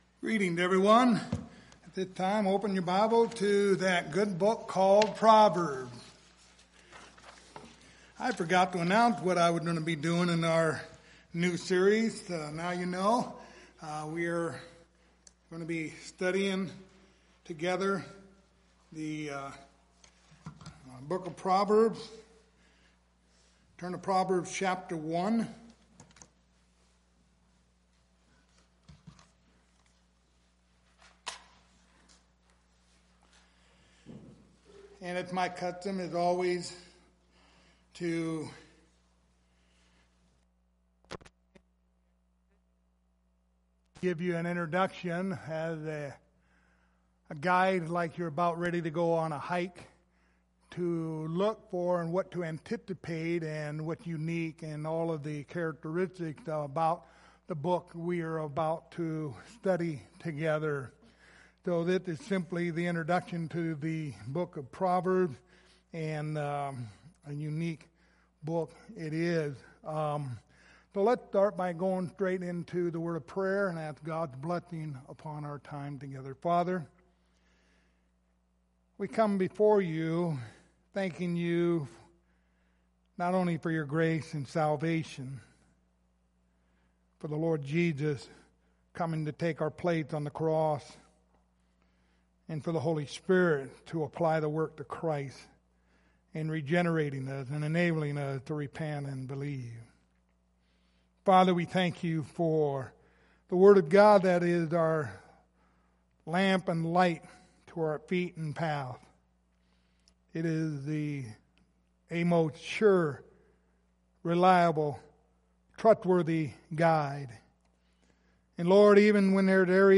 Proverbs 1:1 Service Type: Sunday Morning Topics